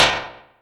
bounce.mp3